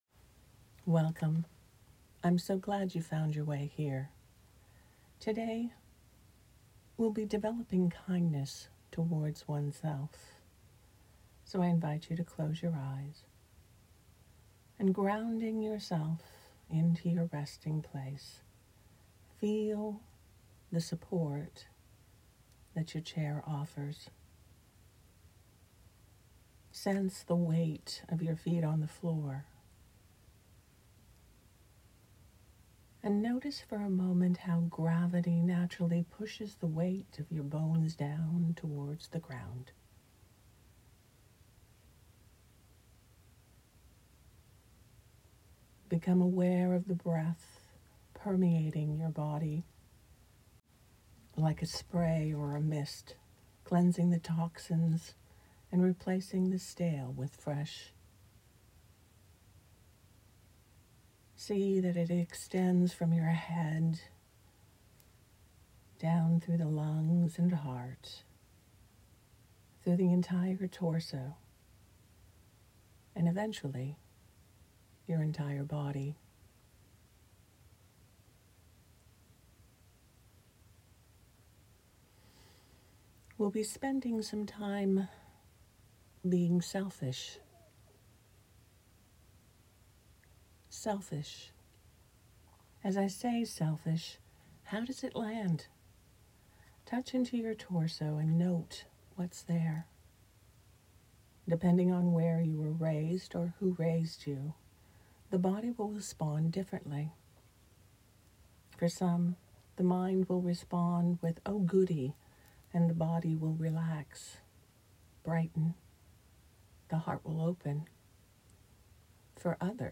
These meditations are offered freely.